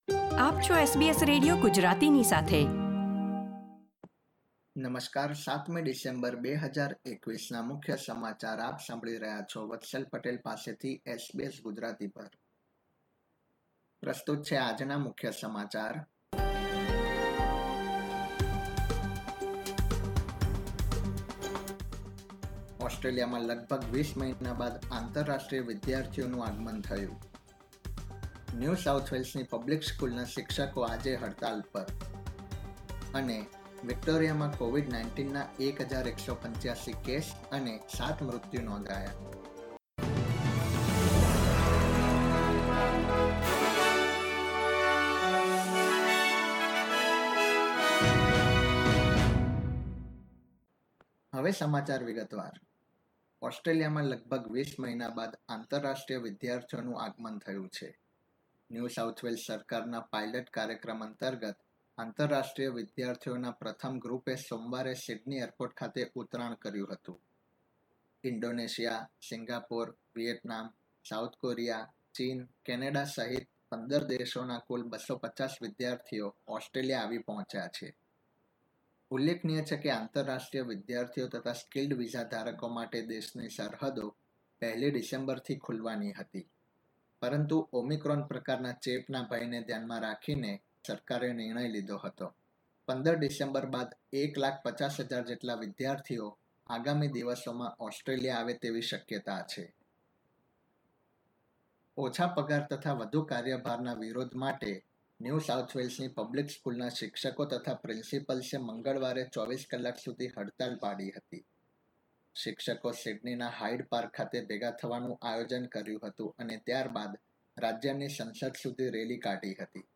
SBS Gujarati News Bulletin 7 December 2021
gujarati_0712_newsbulletin_0.mp3